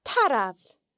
The broad R can also be heard in tarbh (a bull):